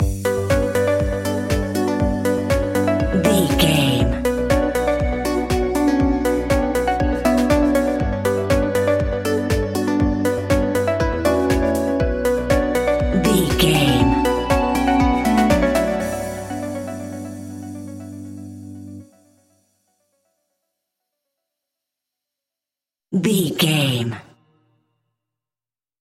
Aeolian/Minor
Slow
groovy
peaceful
meditative
smooth
drum machine
electric guitar
synthesiser
synth leads
synth bass